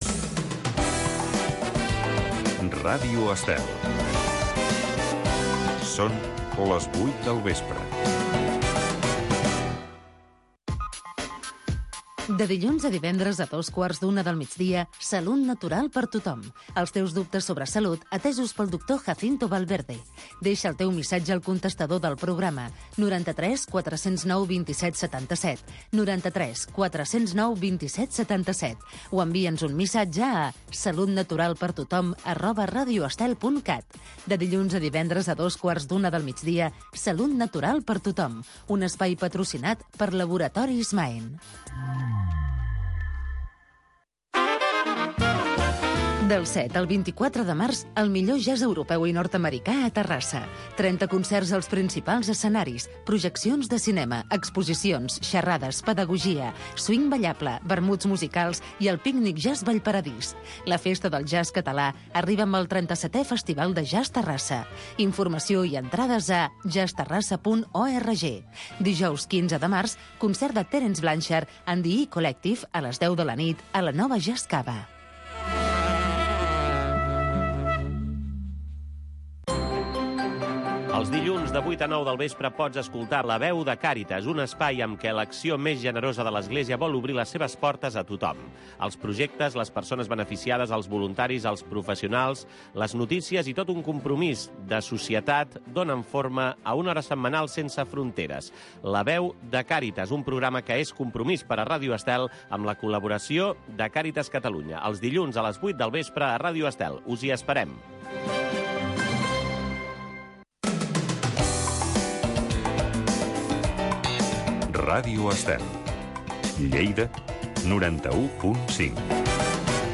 La cantata del diumenge. Audició d’una Cantata de Johann Sebastian Bach, destinada al Diumenge corresponent del calendari luterà